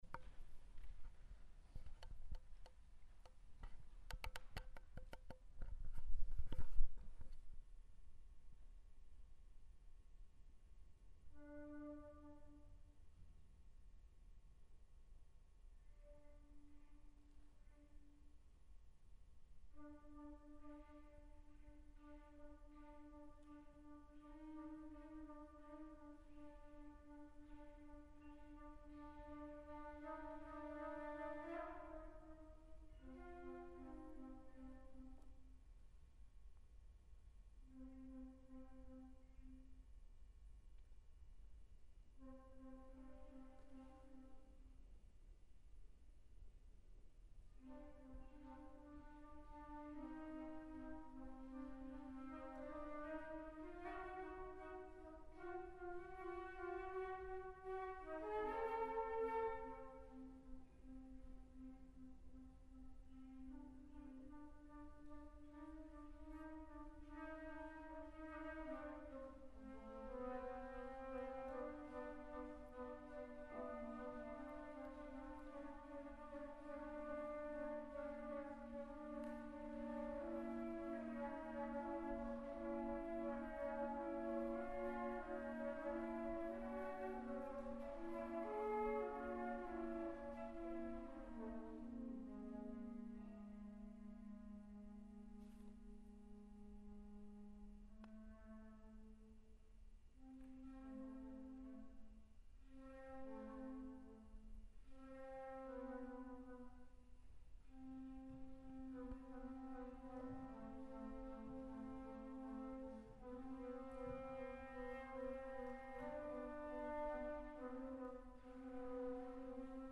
Classical
Epistler for sinfonietta: Sinfonietta written for students at the Norwegian Academy of Music. Live performance from the 1st of March 2013, in Lindemansalen, at the Norwegian Academy of Music.
epistler-for-sinfonietta.mp3